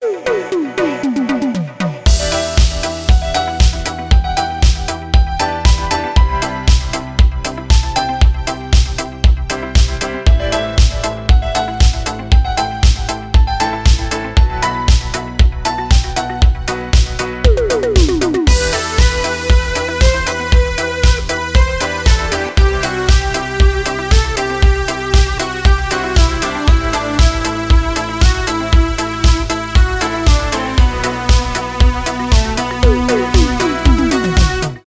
танцевальные , synthwave , без слов